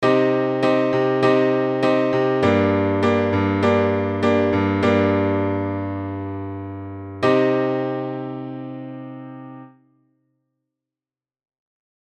サンプルはKey=Cマイナーで、わかりやすくするために、途中に溜めを作っています。
• ナチュラルマイナー
• Im→Vm→Im　Cm→Gm→Cm
終わった感じもしますが、メジャーのときのような緊張感や不安定さから解放された感覚はありませんね。
もちろんこの流れもよく使われますが、緩やかになんとなく終わったという印象を与えます。